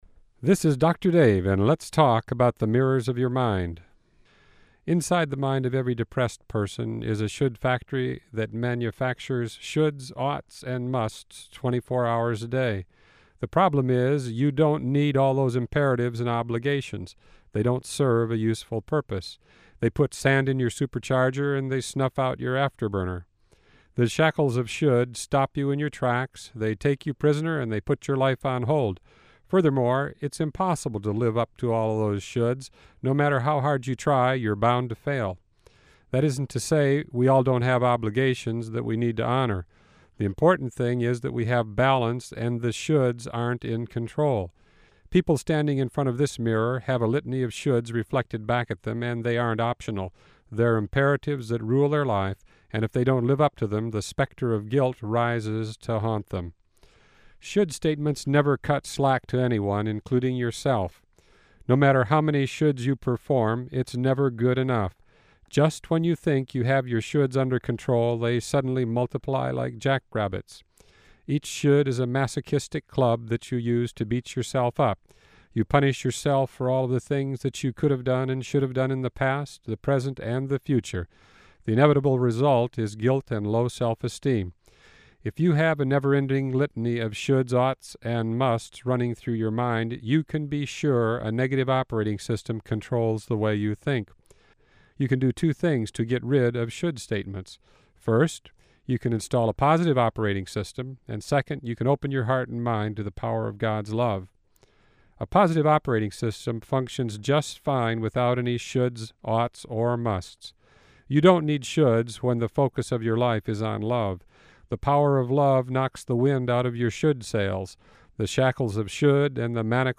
I Positive Radio features short talks that can change your life.